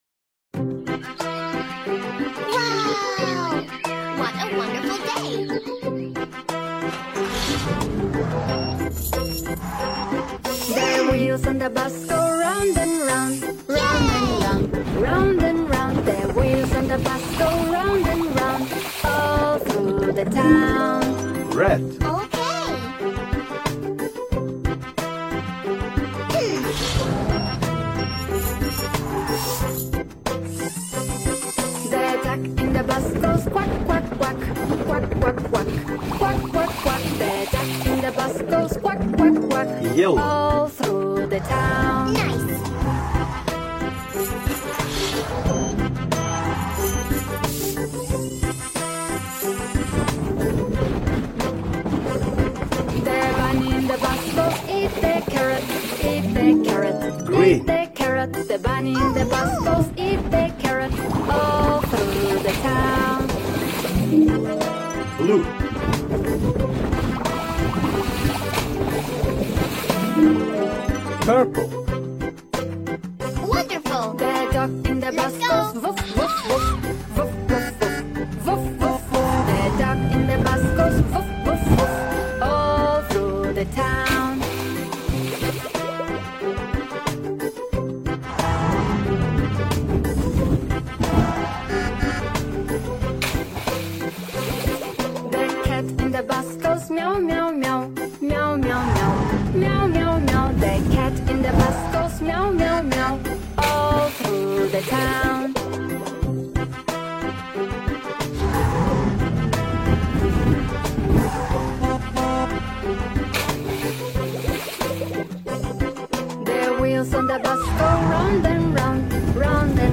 Canciones infantiles